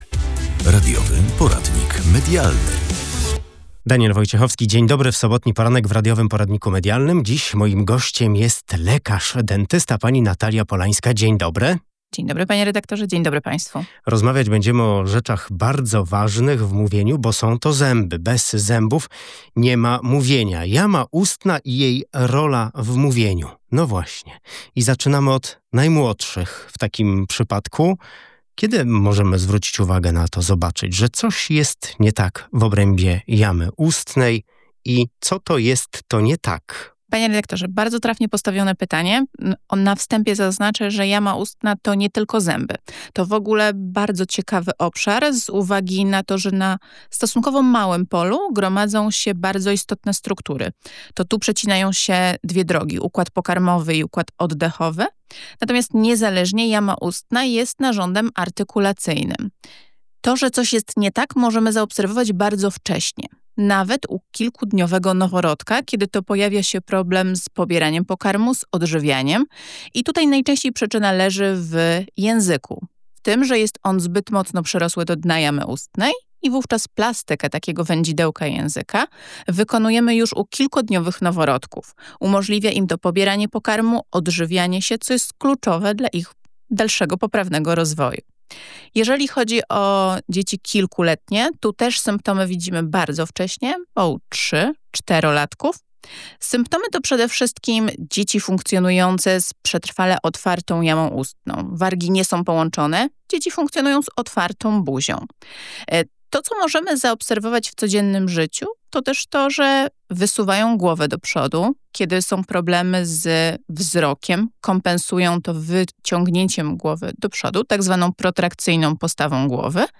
Stan zębów i jamy ustnej wpływa na mowę. Rozmowa z lekarzem dentystą